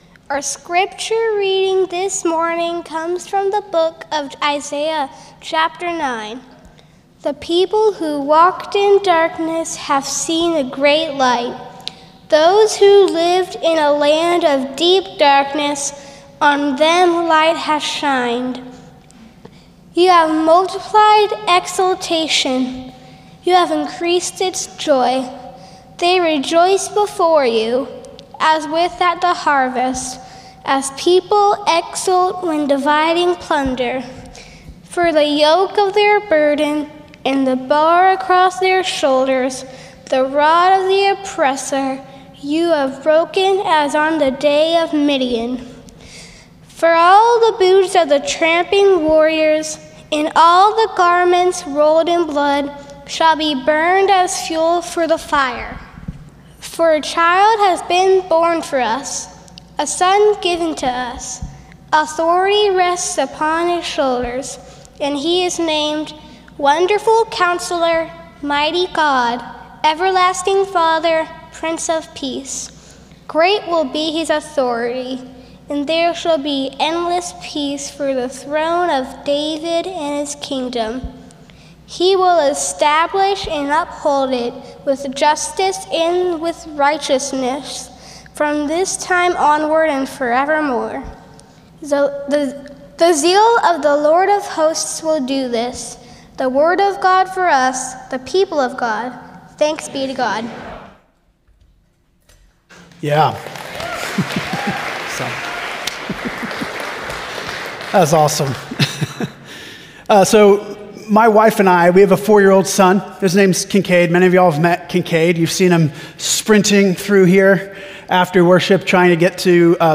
Sermon Series